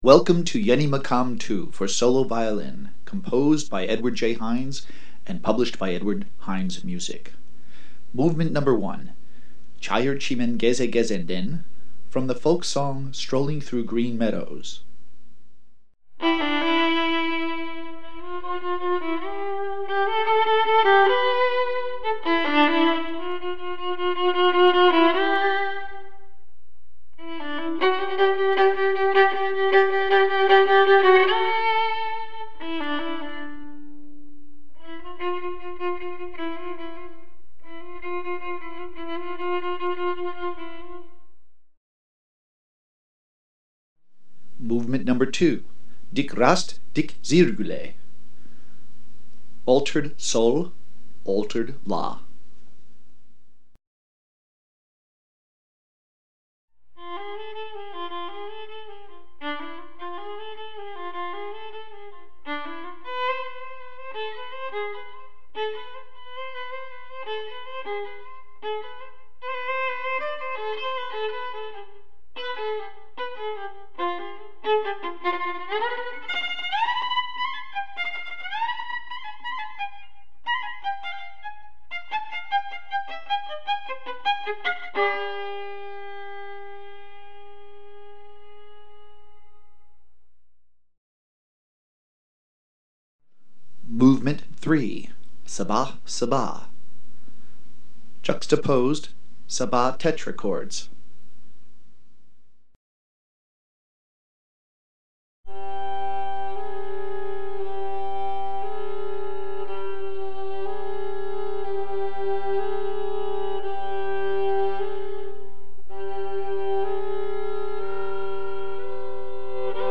for SOLO VIOLIN